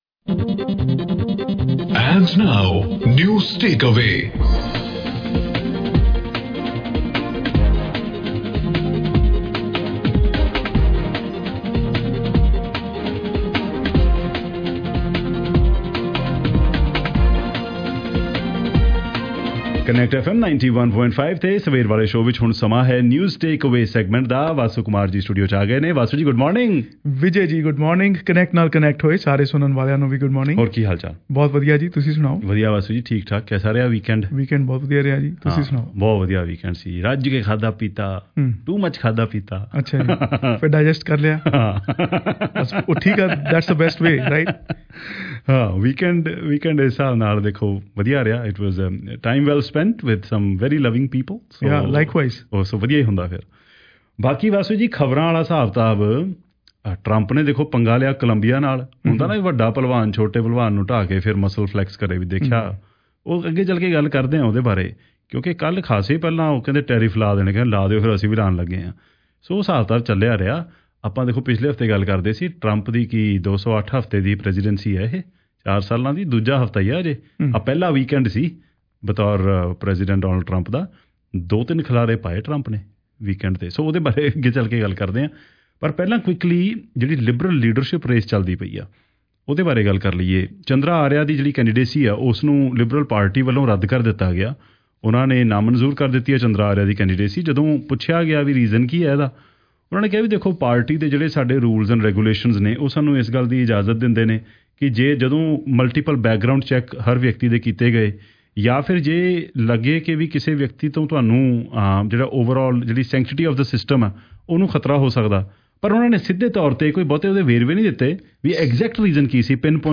Tune in for an engaging and informative discussion on these impactful headlines